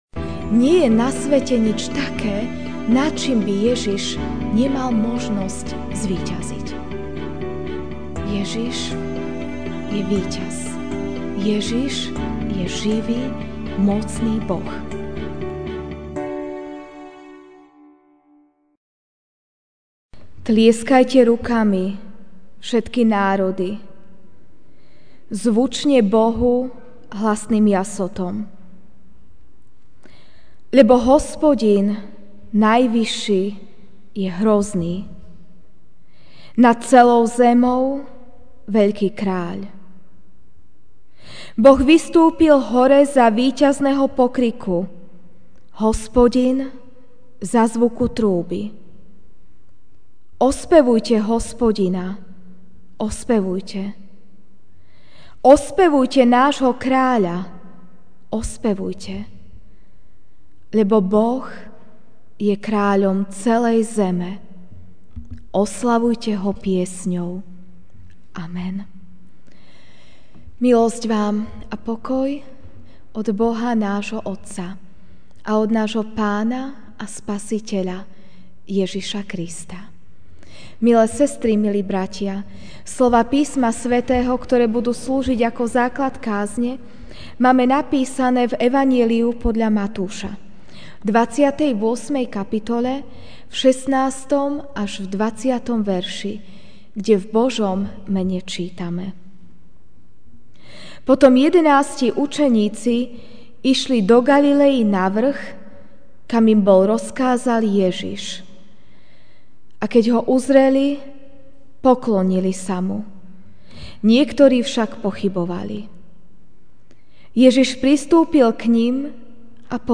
Večerná kázeň: Choďte teda (Mt. 28, 16-20) Potom jedenásti učeníci šli do Galiley na vrch, kam im rozkázal Ježiš, a keď Ho uzreli, poklonili sa Mu; niektorí však pochybovali.